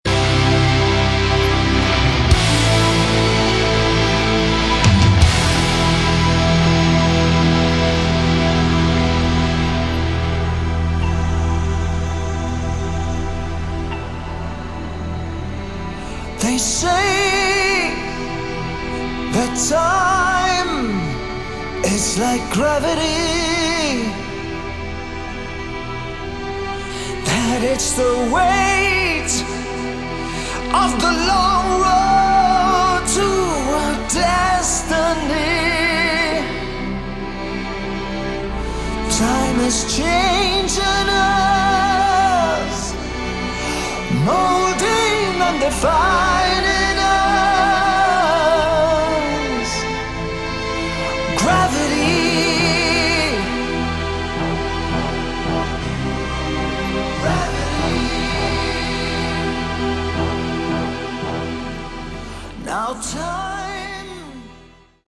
Category: Melodic Metal / Prog / Power Metal
guitars
keyboards
drums
bass
vocals
violin